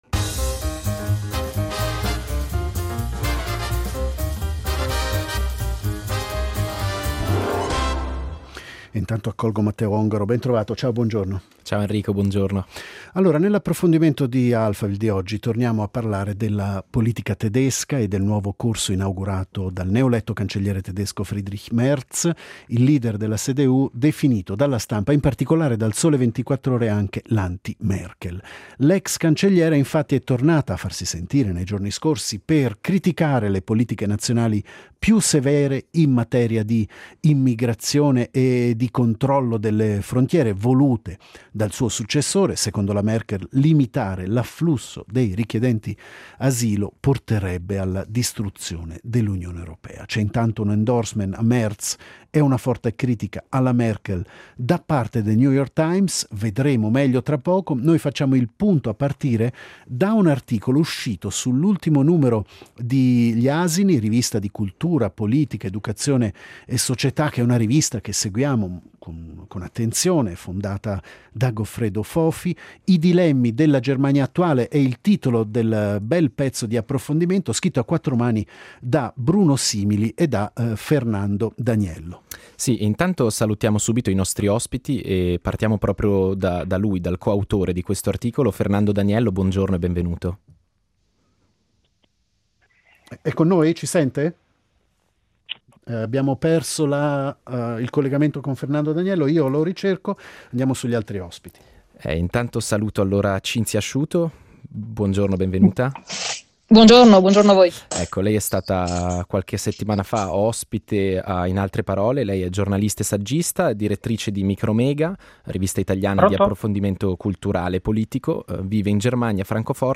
Un confronto tra cancellieri